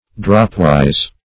Search Result for " dropwise" : The Collaborative International Dictionary of English v.0.48: Dropwise \Drop"wise`\, adv. After the manner of a drop; in the form of drops.